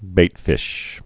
(bātfĭsh)